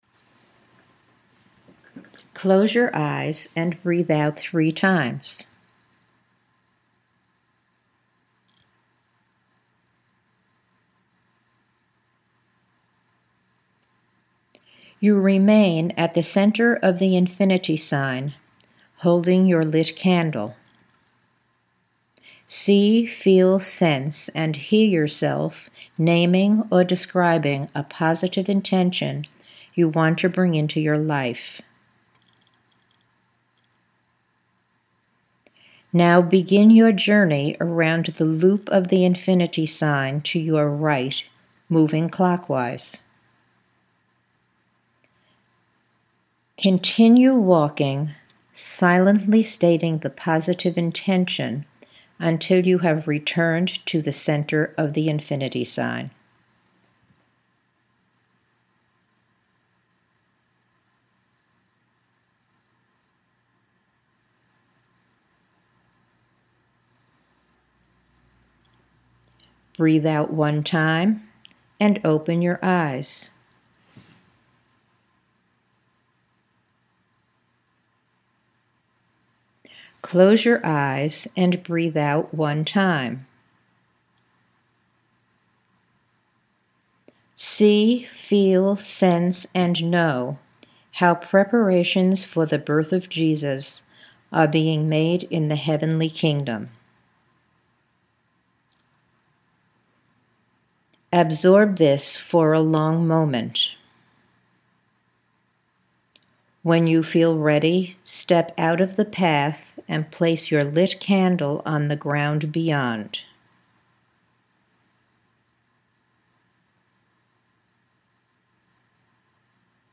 The exercise is not completed until I say Breathe out one time and open your eyes, and you hear the tone.